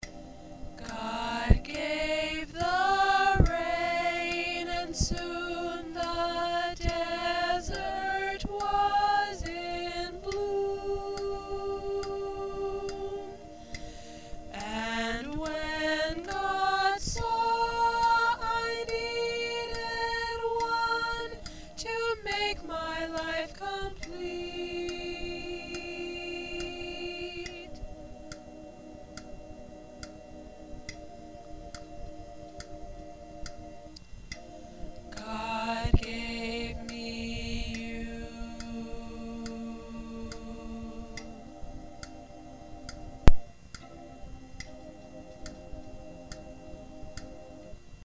A wedding song
Three parts — Soprano, Alto, Tenor